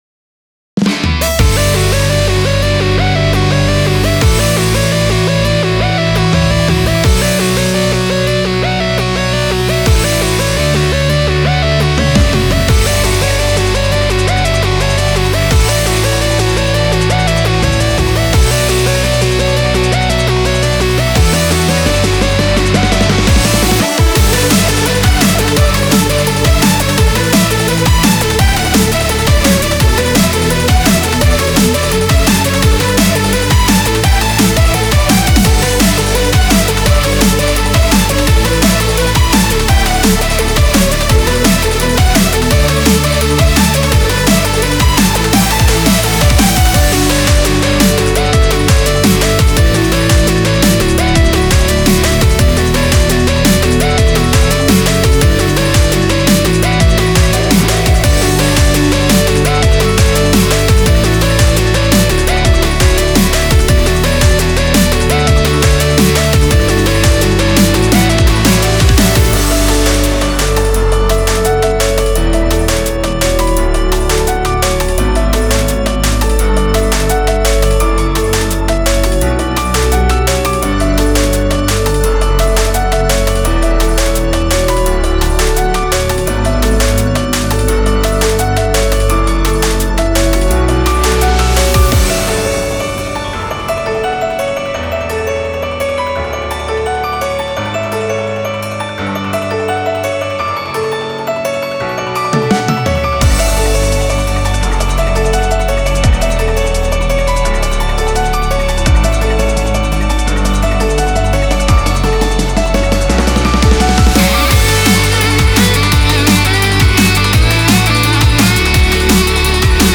electronics
The hope of this piece is really straightfoward and intense.
drum&bass